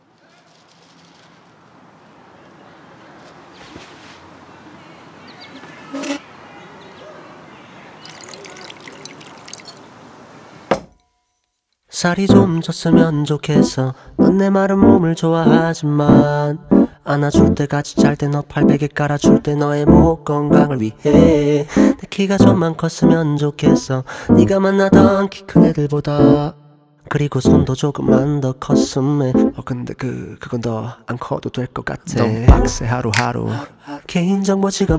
Жанр: Поп / R&b / K-pop / Соул